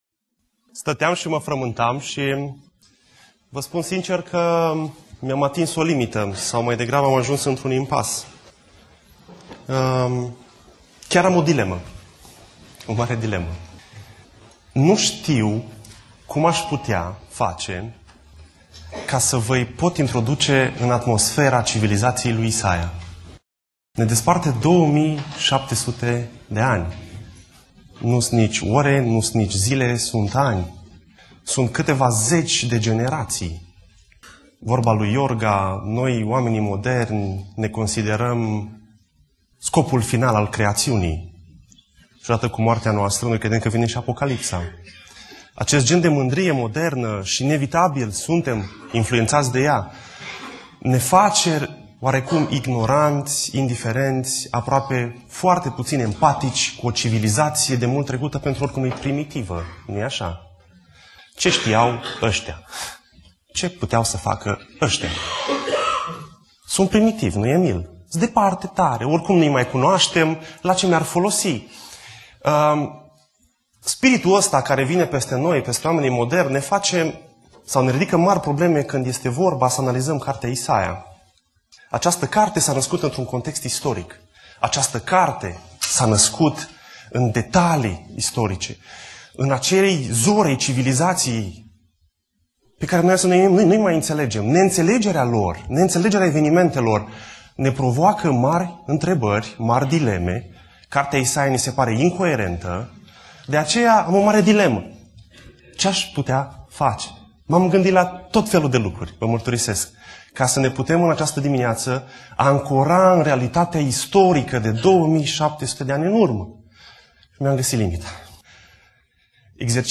Predica Exegeza - Isaia 1-4